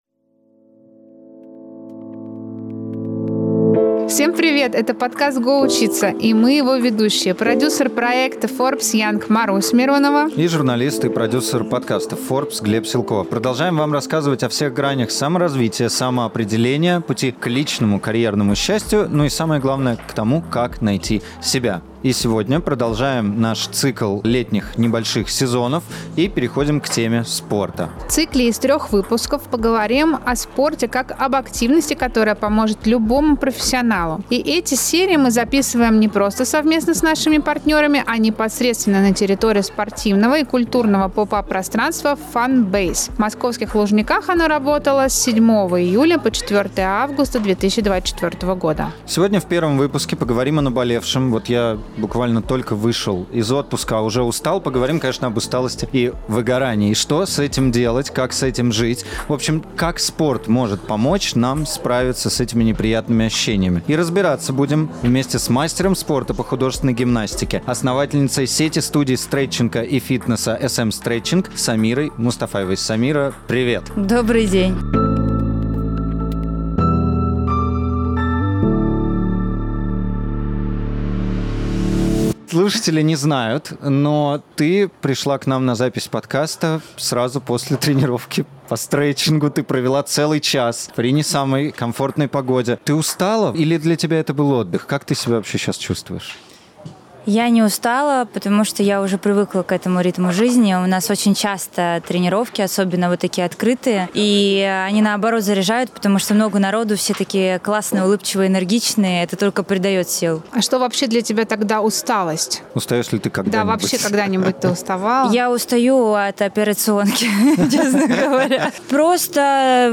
На территории спортивного и культурного поп-ап-пространства Fonbase мы поговорили с мастером спорта по художественной гимнастике